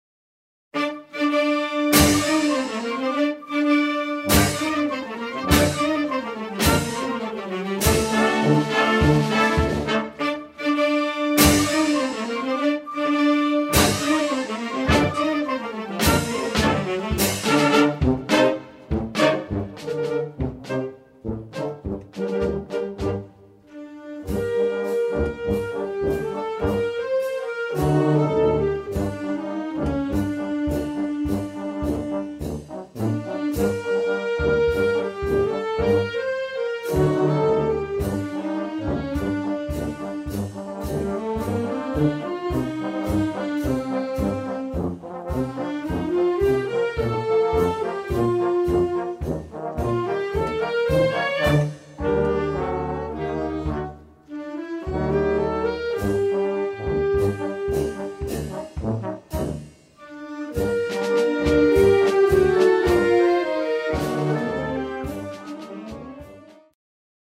Collection : Orchestre d'harmonie
Paso doble taurin pour
orchestre d’harmonie,